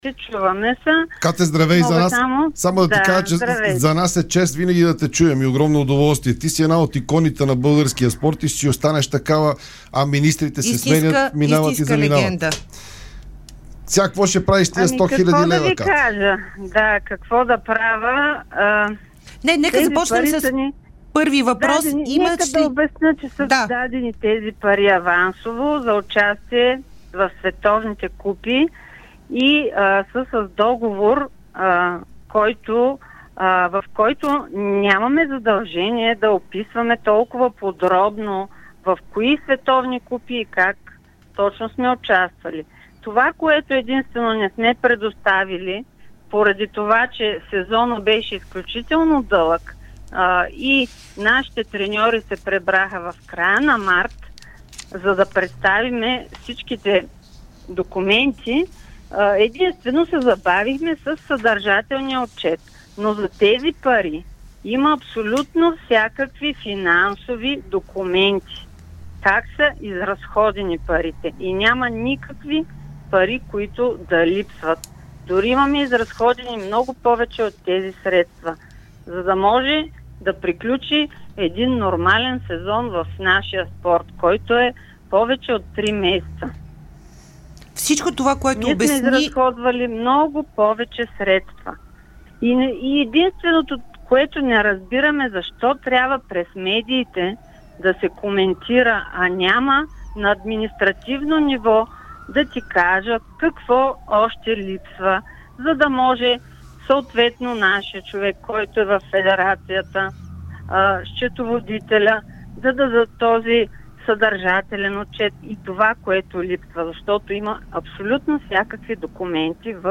Президентът на Българската федерация по биатлон Екатерина Дафовска даде ексклузивно интервю пред Дарик радио и dsport. В него тя разкри, че министърът на спорта Радостин Василев отказва да се срещне с нея, за да обсъдят скандалите в българския биатлон, свързани с тези близо 100 000 лева, които министърът заяви, че федерацията по биатлон трябва да възстанови.